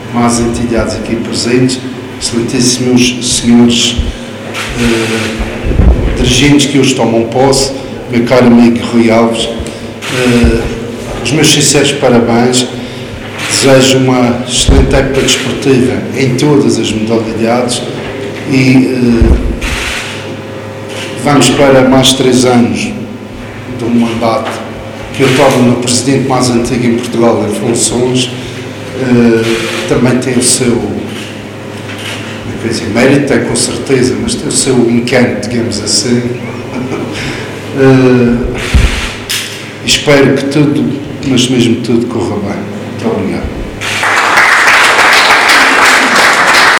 A cerimónia foi acompanhado por sócios e simpatizantes do clube e por representantes das diversas entidades oficiais.
Discursos: